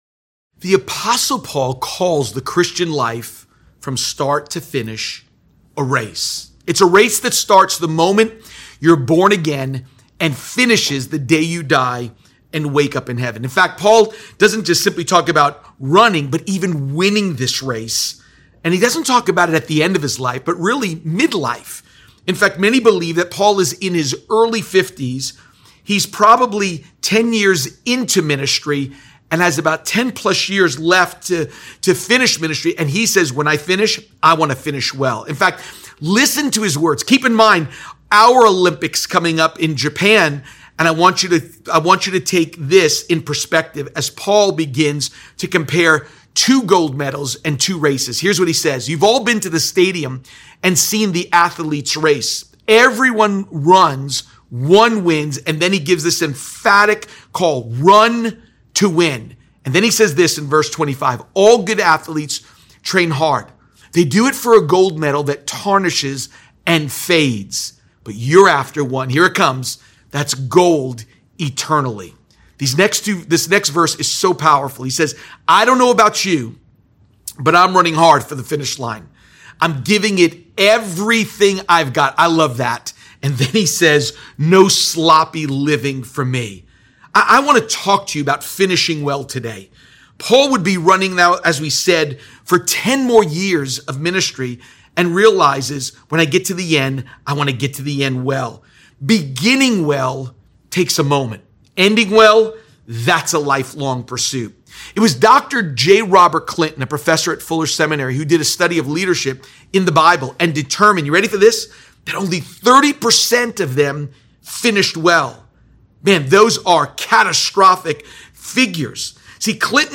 Diseased Feet | Times Square Church Sermons